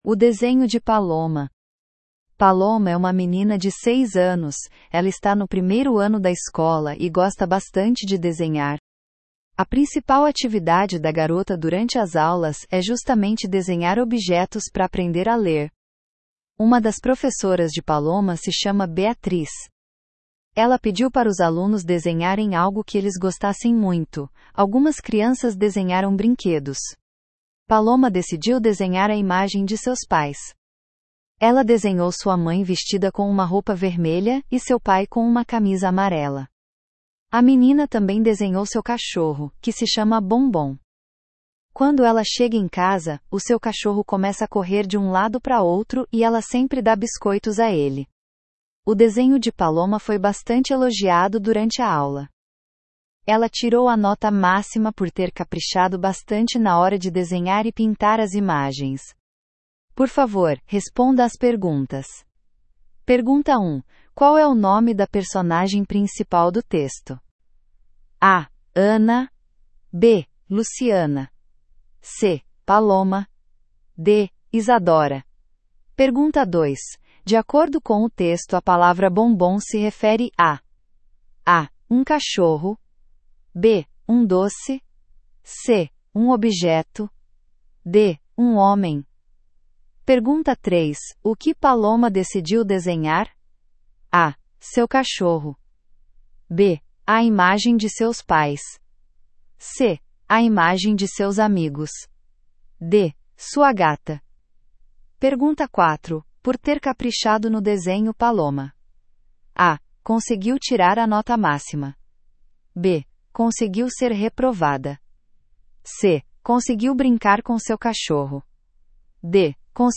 Brasil